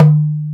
DUMBEK 3A.WAV